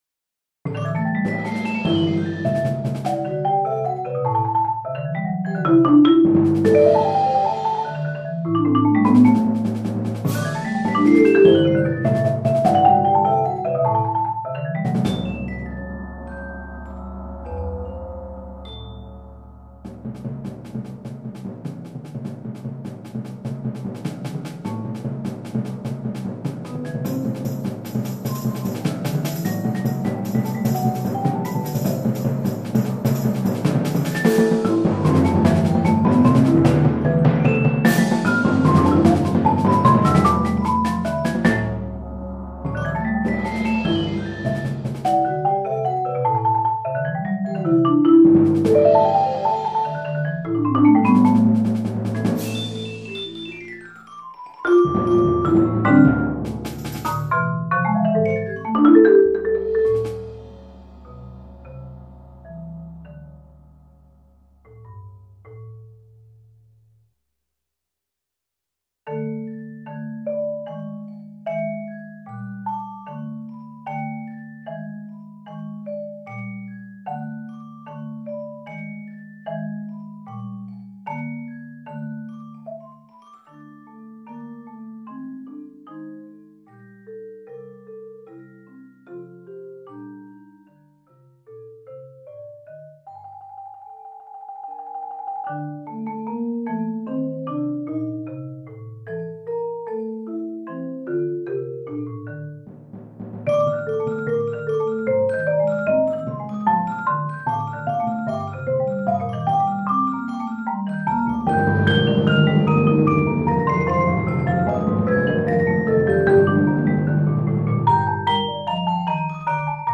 Hidden Streams (timpani, cymbals, snare drum, celesta, and marimba) – 2007